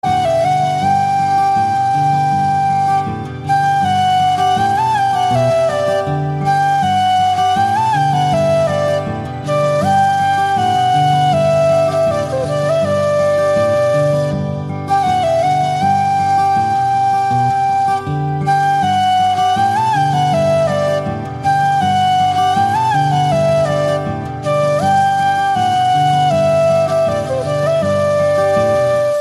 Hindi Ringtones